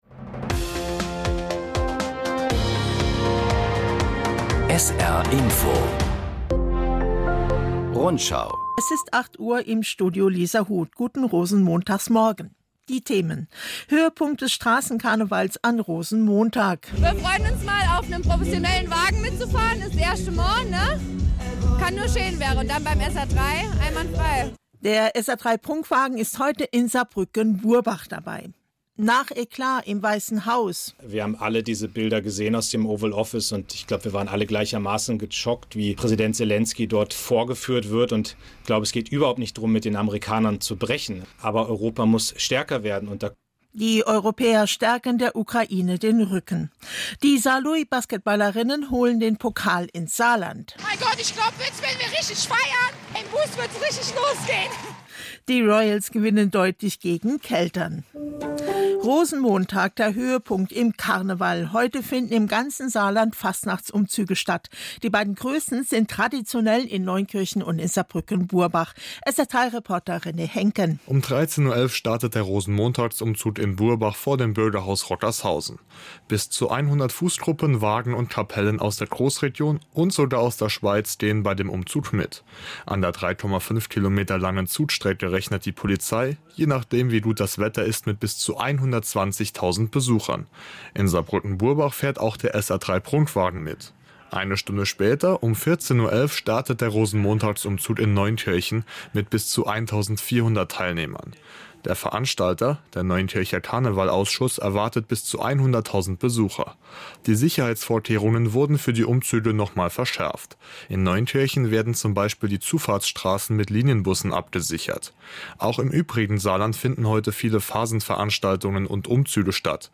Zehn Minuten Information aus aller Welt und aus der Region … continue reading 5 bölüm # Nachrichten